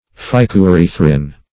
Meaning of phycoerythrin. phycoerythrin synonyms, pronunciation, spelling and more from Free Dictionary.
Phycoerythrin \Phy`co*e*ryth"rin\